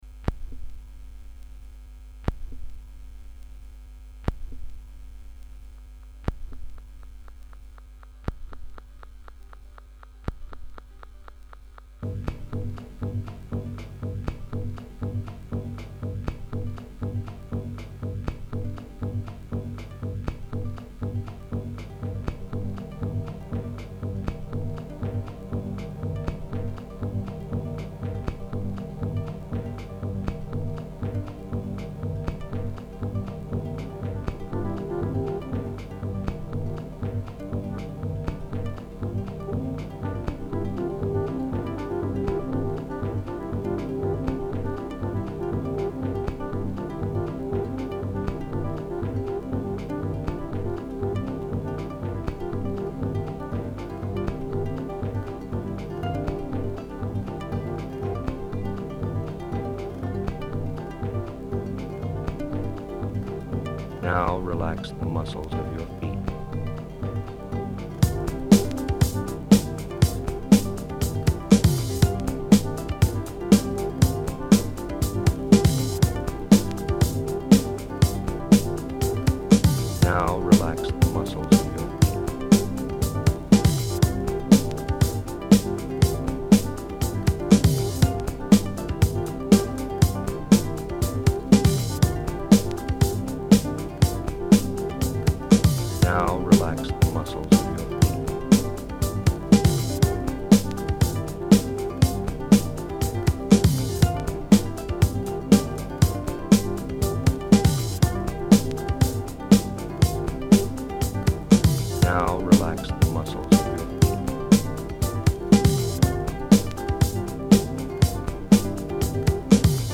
It's not finished, it's a bit pointless and it doesn't really go anywhere, but I quite like the mood.
Like the Autobahn era Kraftwerk vibe in the first bit.